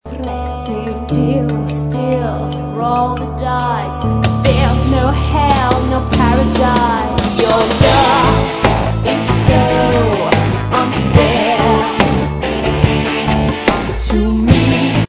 électro-pop